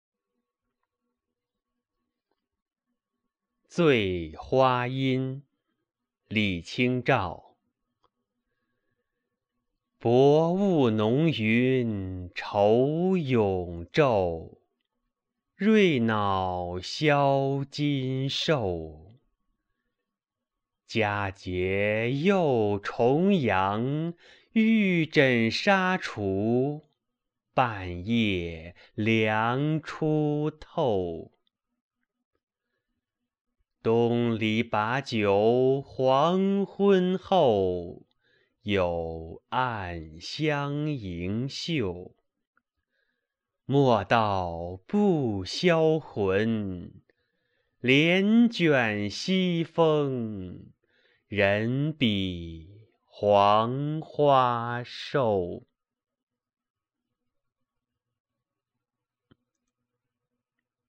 醉花阴（李清照）mp3朗读